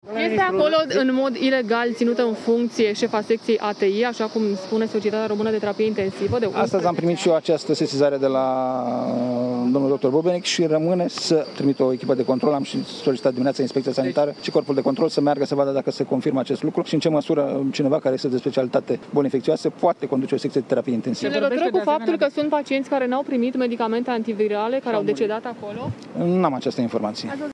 Ministrul Sănătății spune că urmează să fie trimisă o echipă de control pentru a vedea acest lucru și, totodată, în ce măsură cineva de specialitate boli infecțioase poate conduce o secție de Terapie Intensivă: